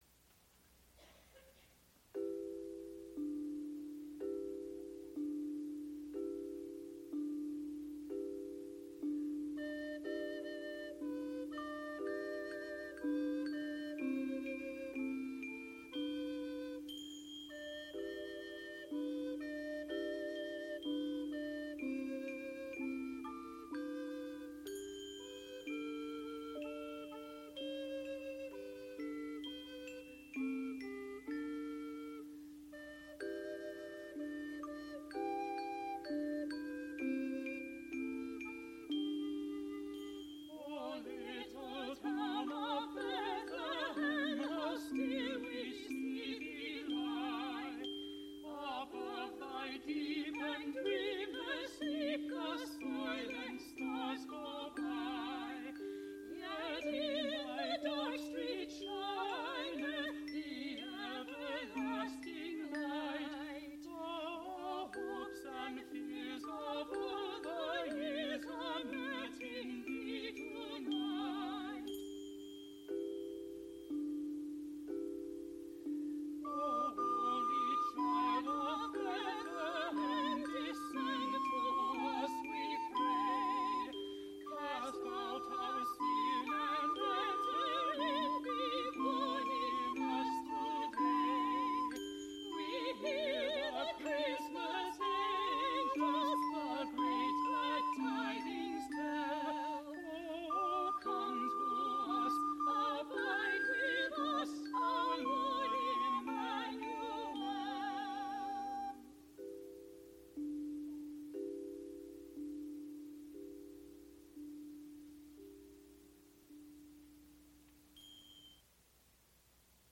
Spoken intro for A Visit from St. Nicholas and Friends concert
musical performances, Sound